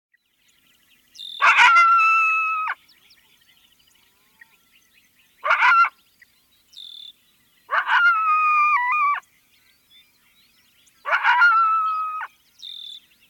Coyote
(Canis latrans)
Coyote-edit.mp3